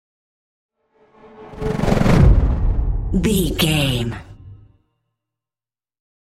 Chopper whoosh to hit
Sound Effects
Atonal
dark
futuristic
intense
tension